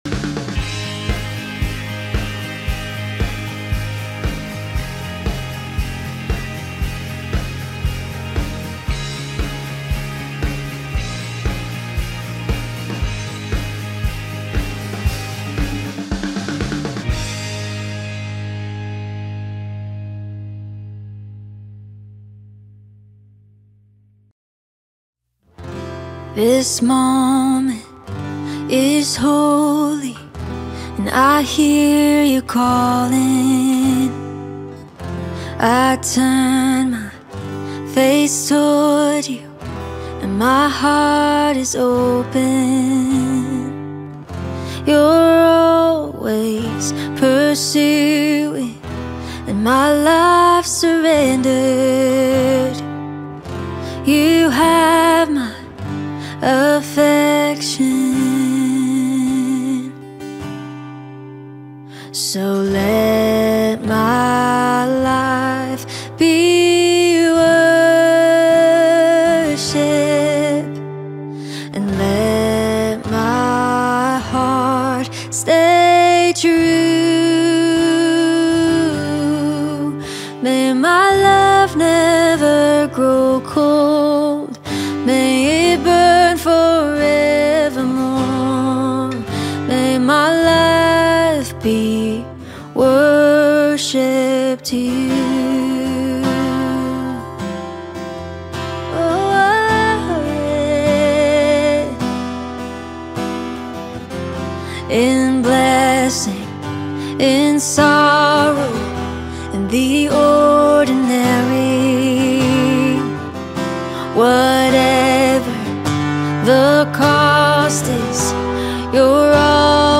Sunday Service I The Righteous Judgment of God: Romans 2:1-29